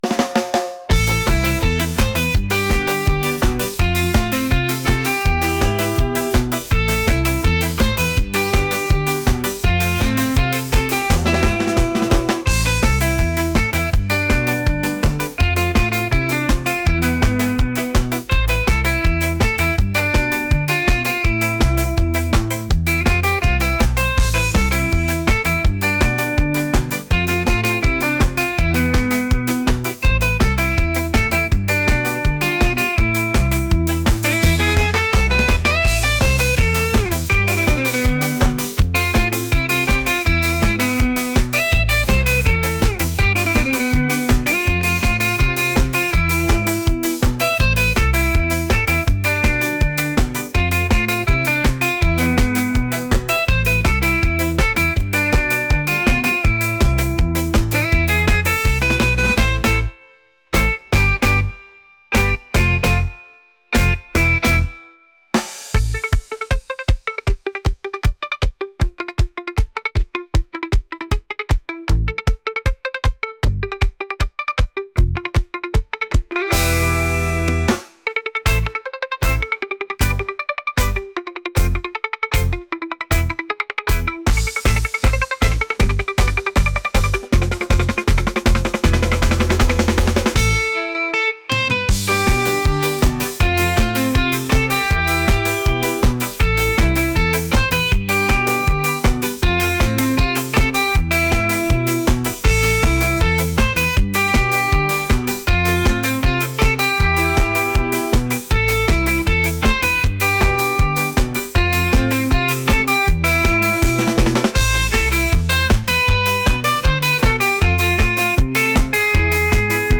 rock | energetic | reggae